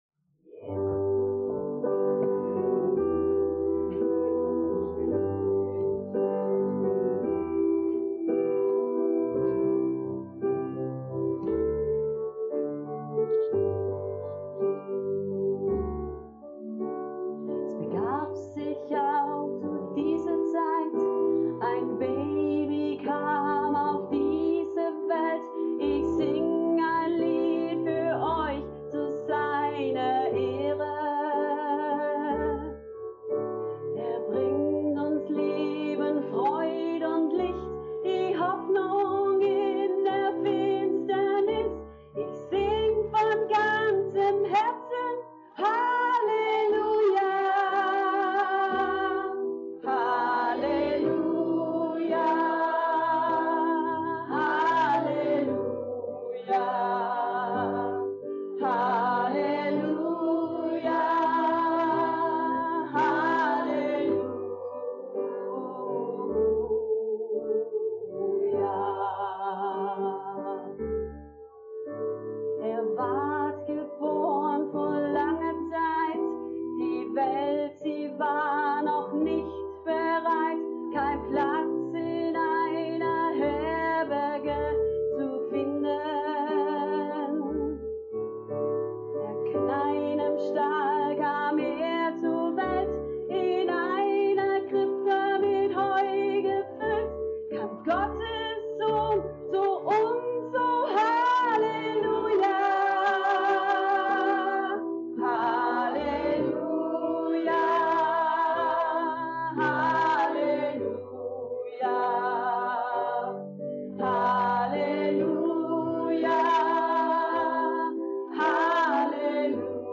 | Veranstaltung
bei unserer Geburtagsfeier für Jesus am 20.12.2025